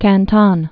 (kăntŏn, kăntŏn, -tən)